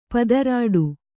♪ padarāḍu